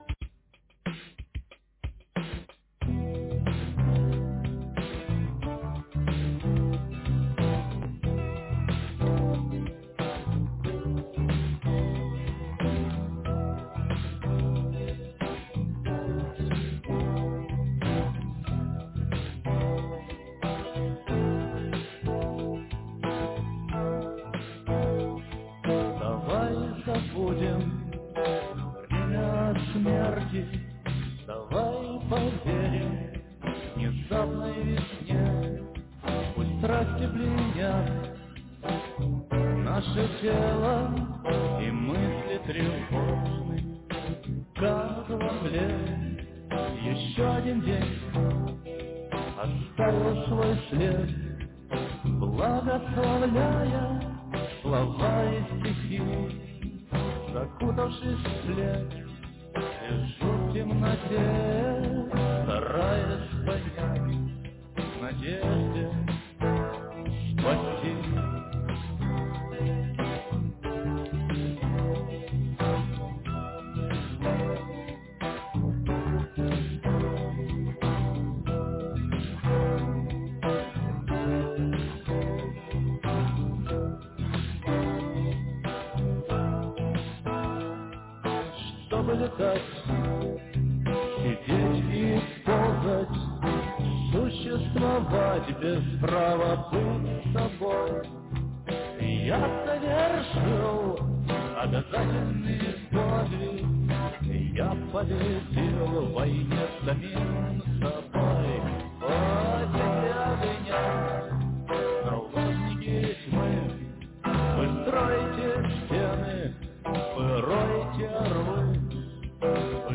Пожалуйста, послушайте под гитару. friends